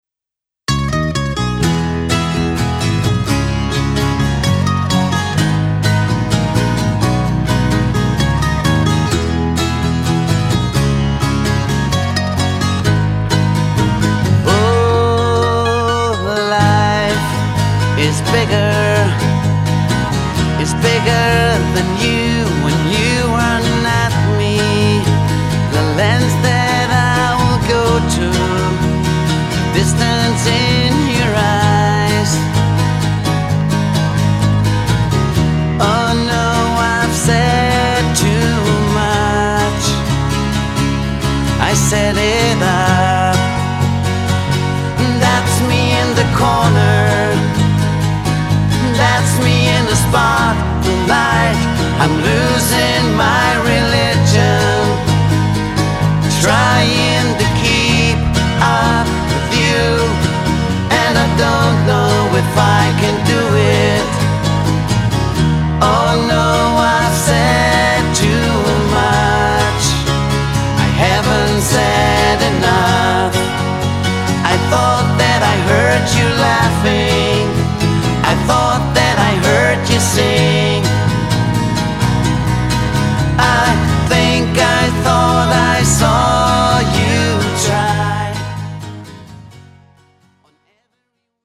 Gitarren Duo